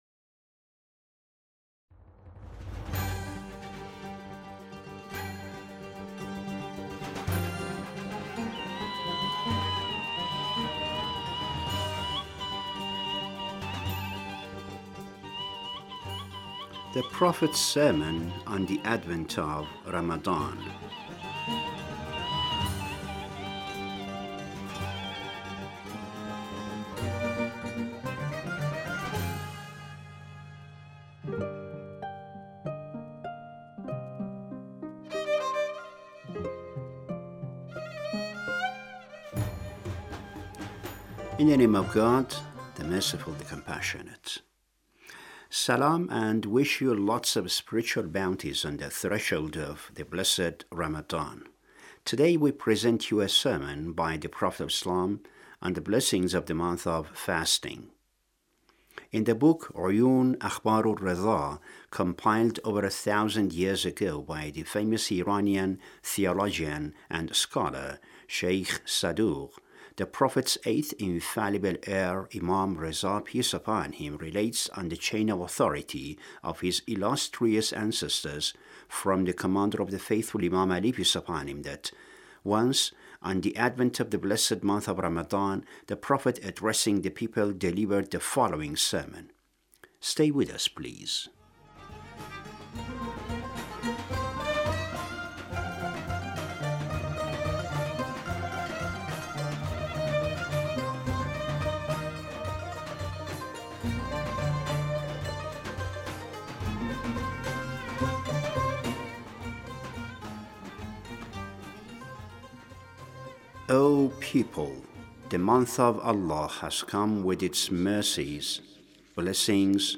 The Prophet's sermon on the advent of Ramadhan